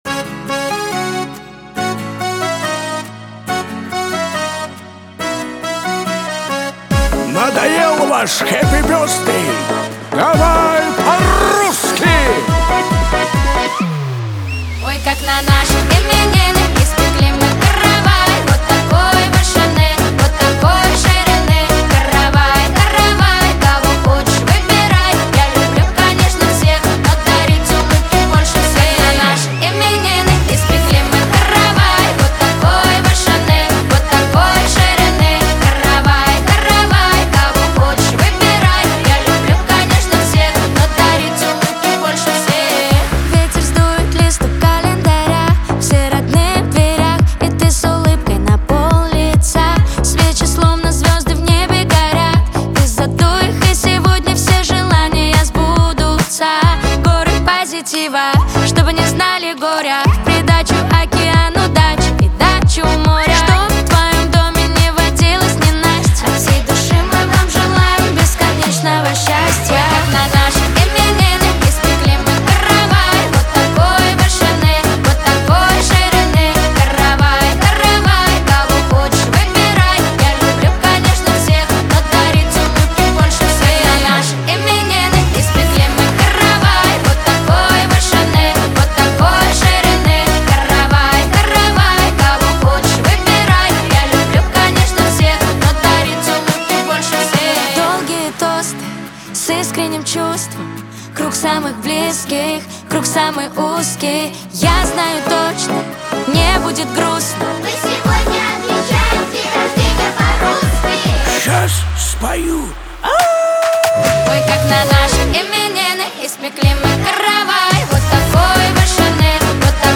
зажигательная песня